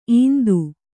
♪ īndu